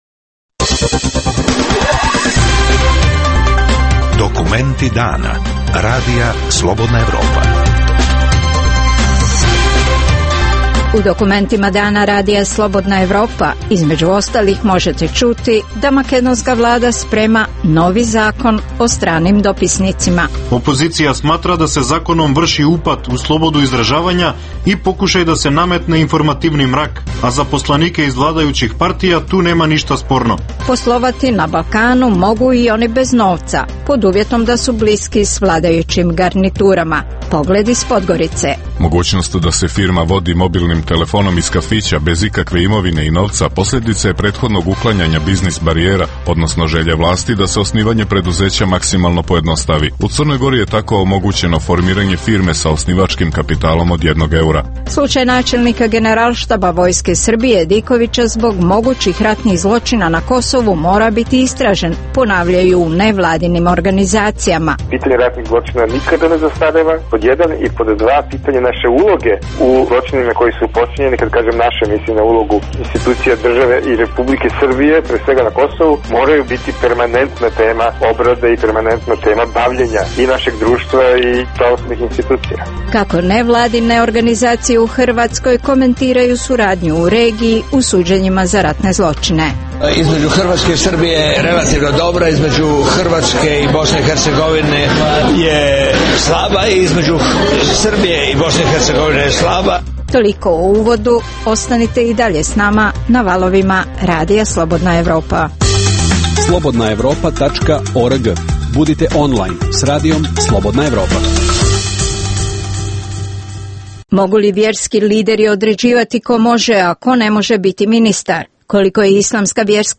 - Gost Radija Slobodna Evropa je poglavar Islamske vjerske zajednice Mustafa Cerić.